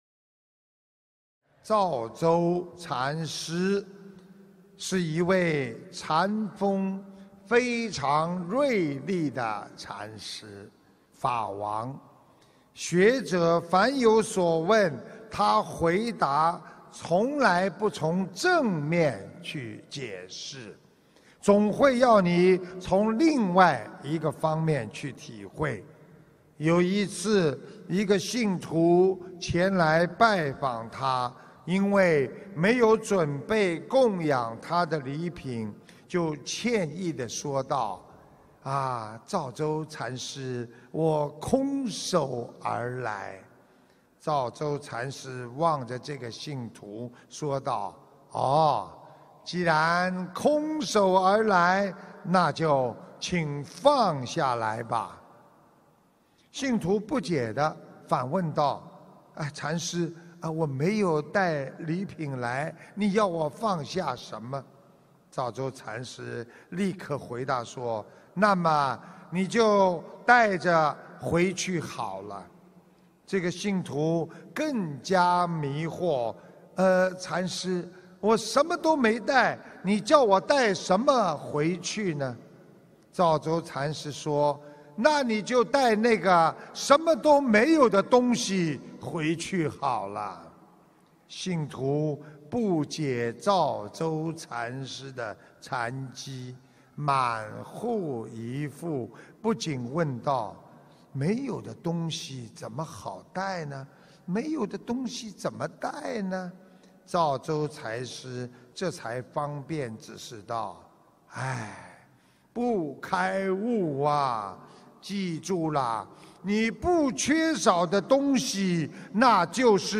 心灵净土»心灵净土 弘法视频 师父讲故事 视频：102.《提得起放得下 才是活在当下》印度尼西亚雅 ...
音频：《提得起放得下 才是活在当下》印度尼西亚雅加达开示2017年4月21日!